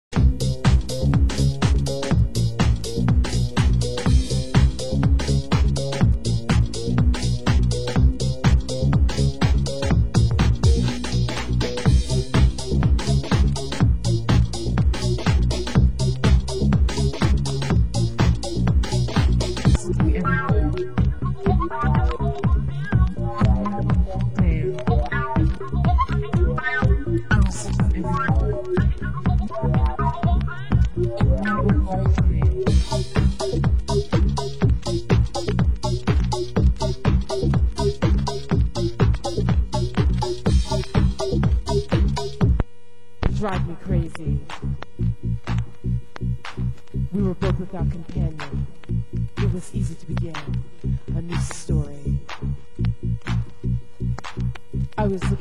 Genre: UK House
club mix